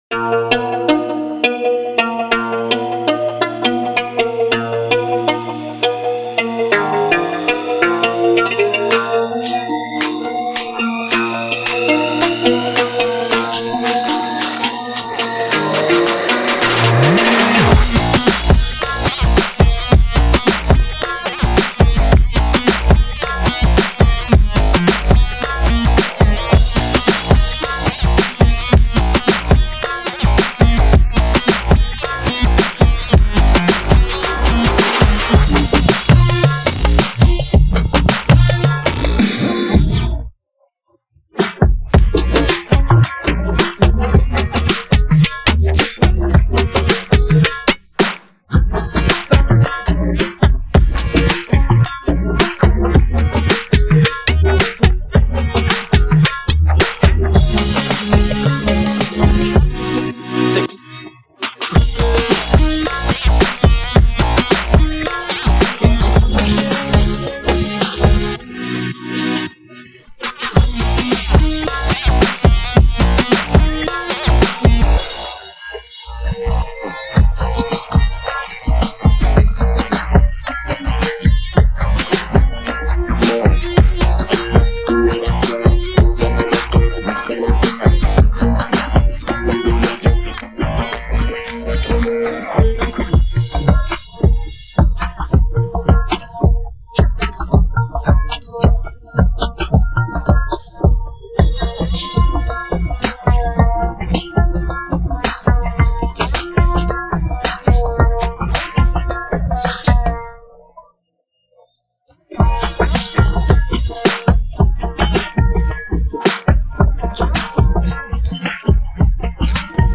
پخش نسخه بی‌کلام